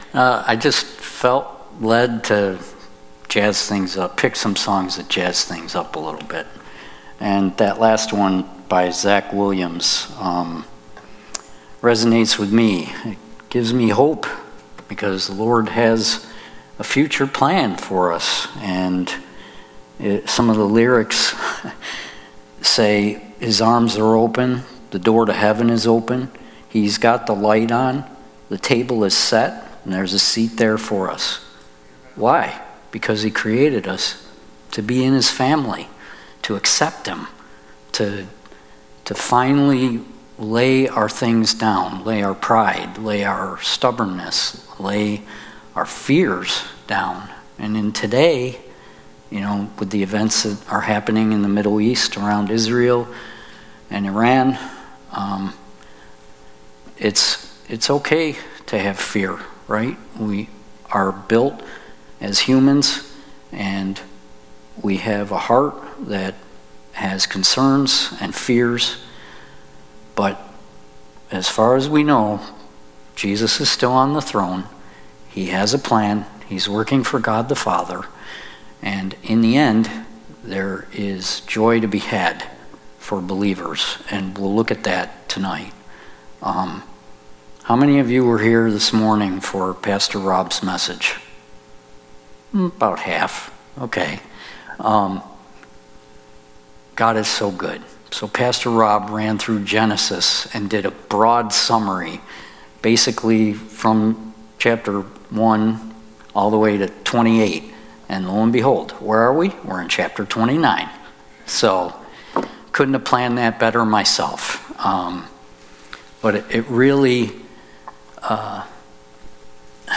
Sunday Night Bible Study